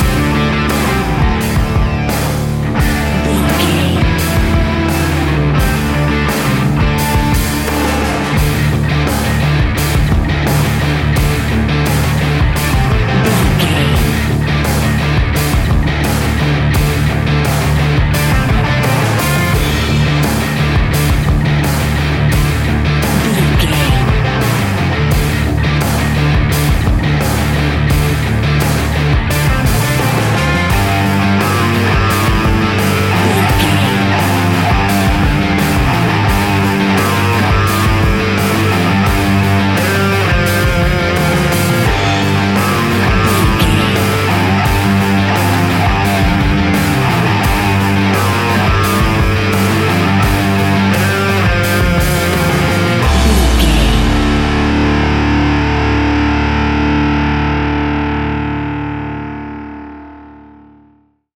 Ionian/Major
D♭
hard rock
heavy rock
distortion
instrumentals